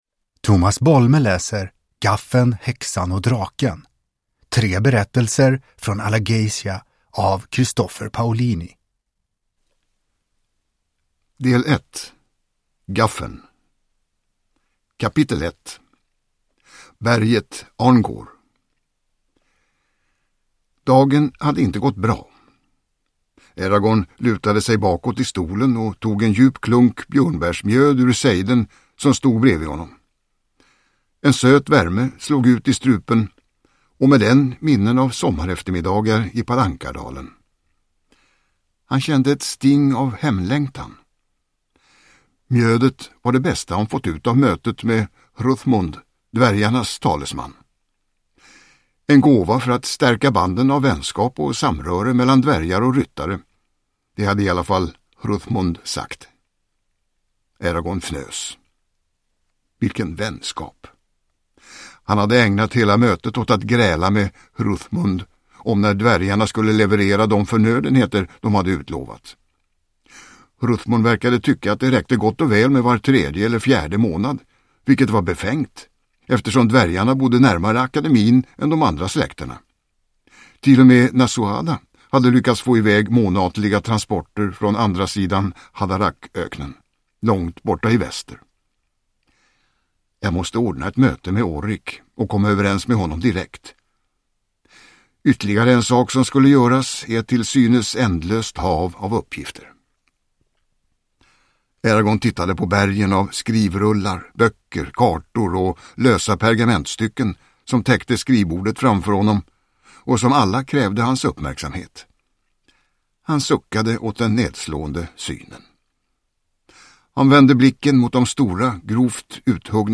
Uppläsare: Tomas Bolme